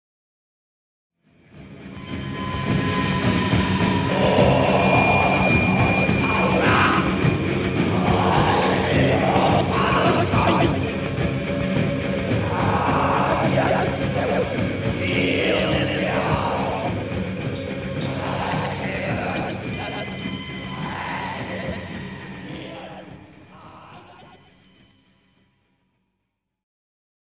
Genere: heavy metal
Incomprensibile